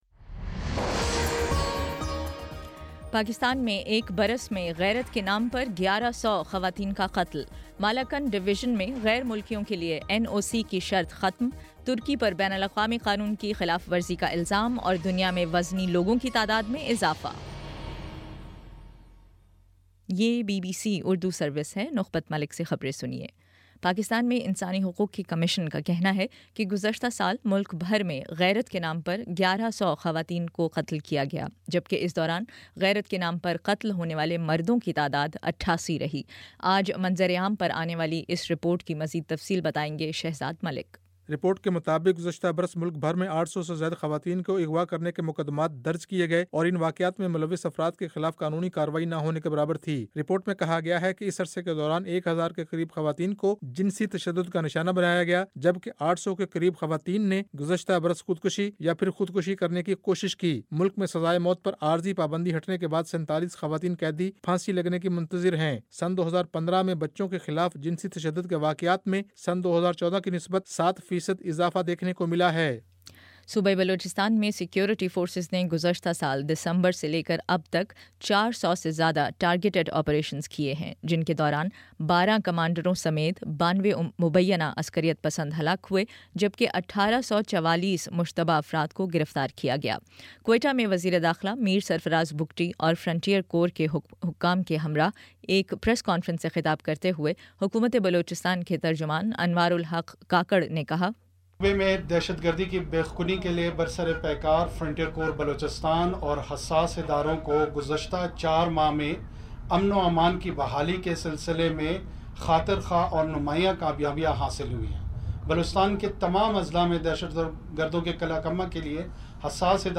اپریل 01: شام سات بجے کا نیوز بُلیٹن